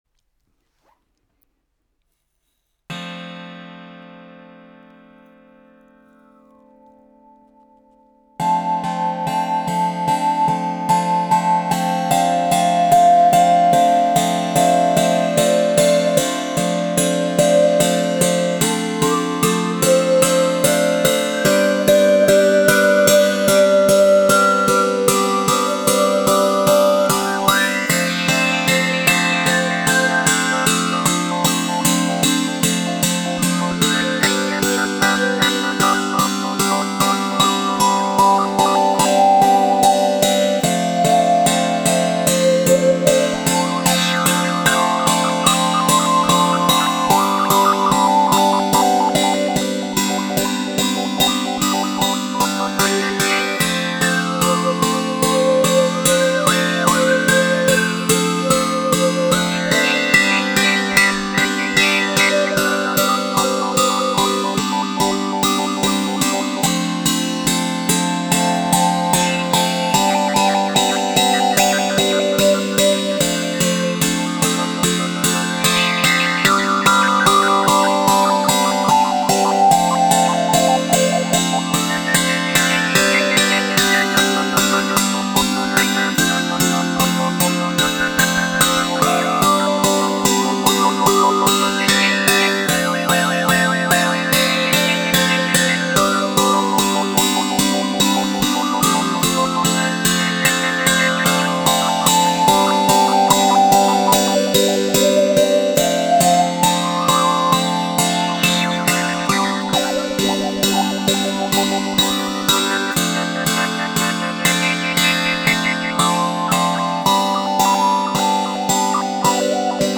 COSMICBOW 6 CORDES avec cuillère harmonique
Le son est très chaud et attrayant…entrainant.
Les sons proposés ici sont réalisés sans effet.
6-cordes.flac